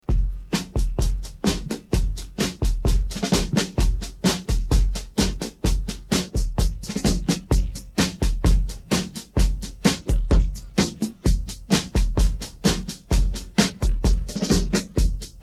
pattern_gloria.mp3